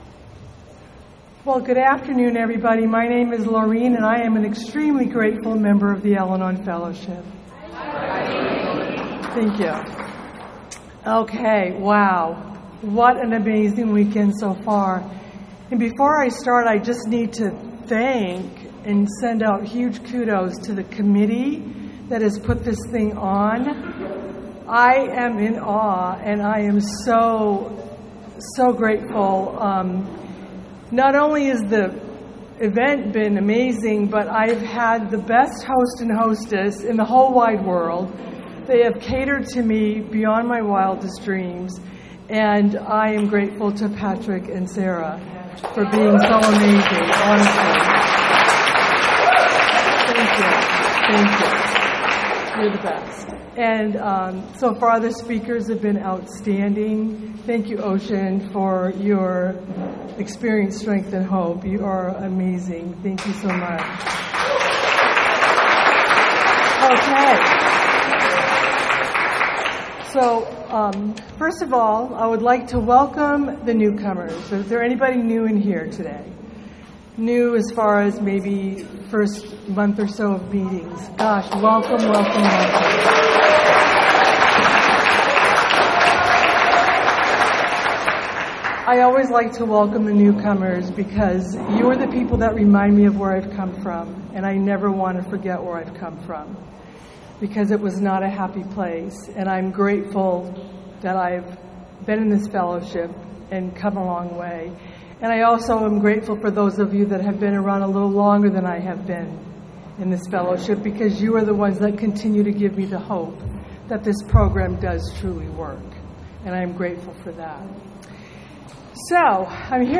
2024 Serenity By The Sea - Ventura CA